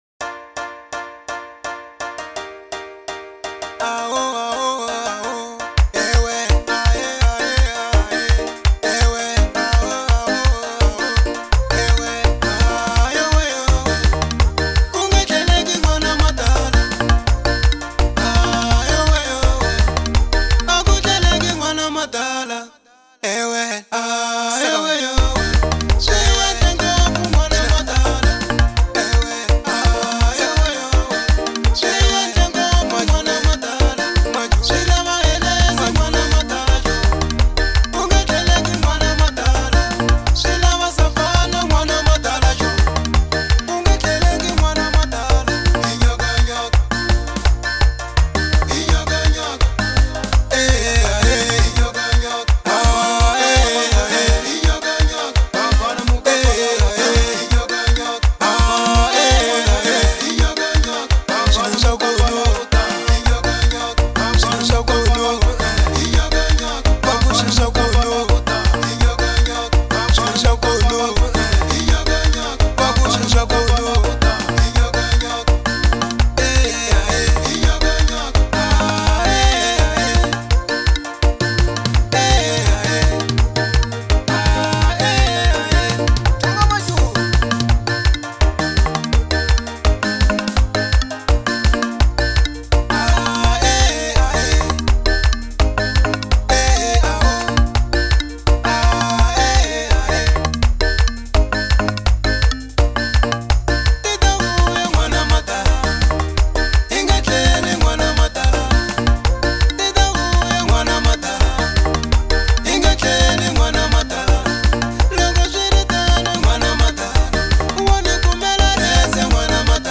04:12 Genre : Xitsonga Size